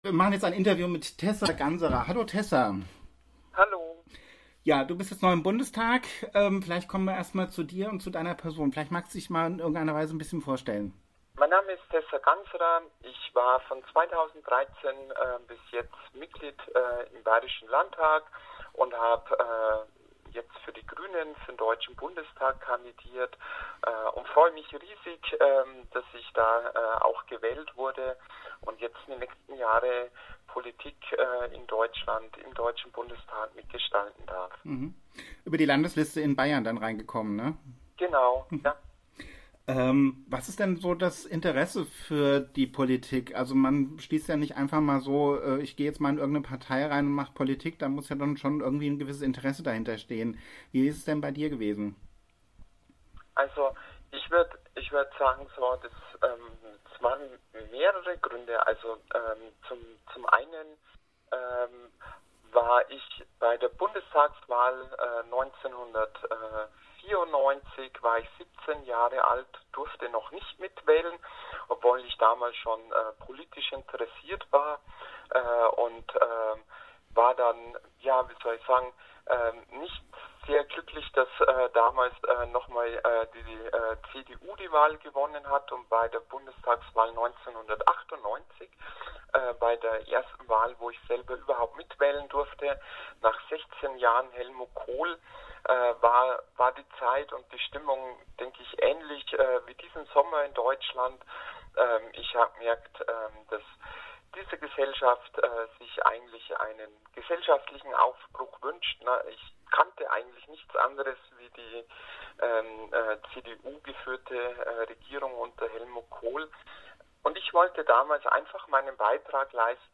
Wir sprechen mit Tessa Ganserer